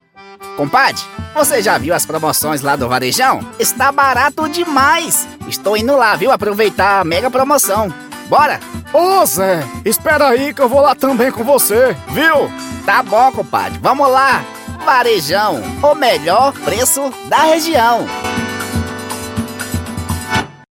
DEMONSTRATIVO CARICATA: